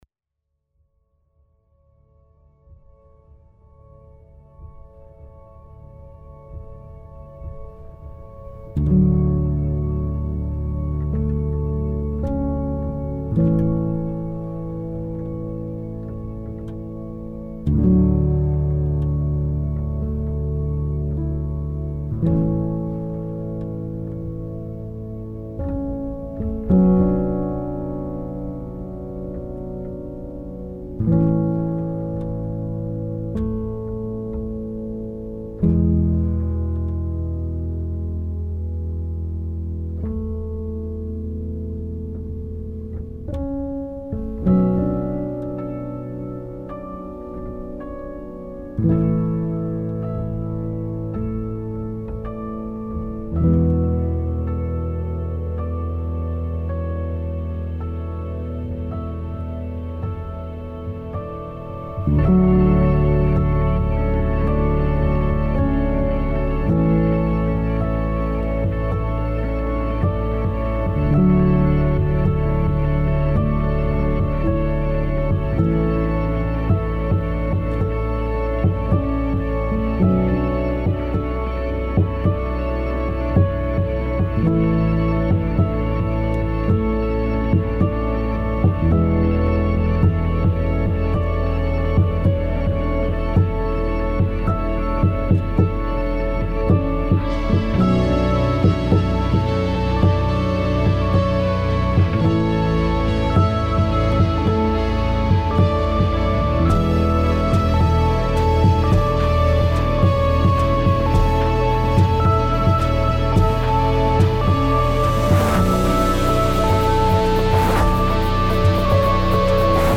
سبک امید‌بخش , موسیقی بی کلام